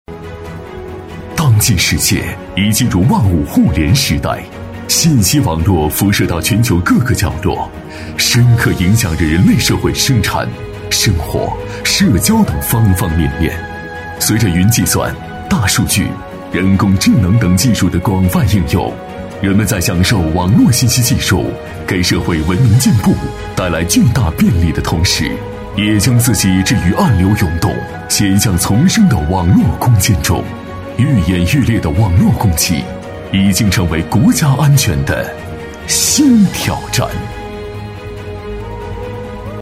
男B34-暖场视屏-激情大气
男B34-暖场视屏-激情大气.mp3